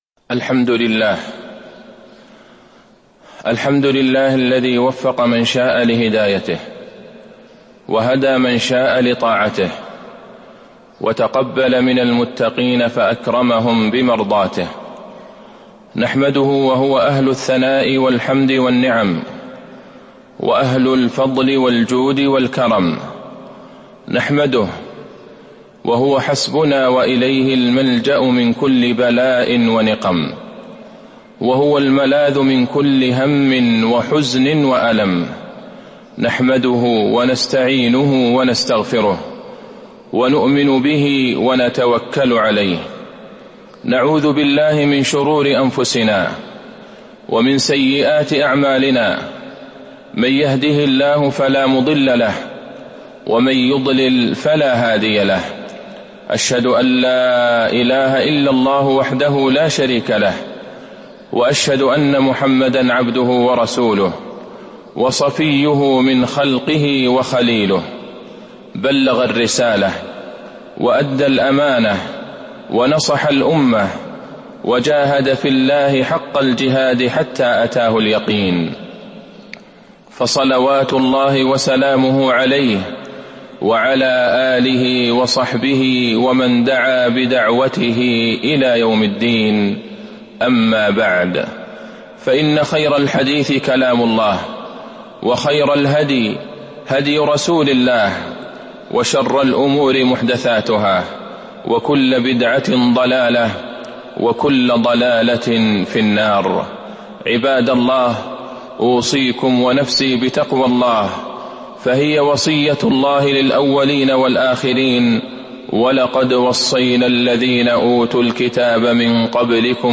تاريخ النشر ٩ شوال ١٤٤٢ هـ المكان: المسجد النبوي الشيخ: فضيلة الشيخ د. عبدالله بن عبدالرحمن البعيجان فضيلة الشيخ د. عبدالله بن عبدالرحمن البعيجان المداومة على الأعمال الصالحة بعد رمضان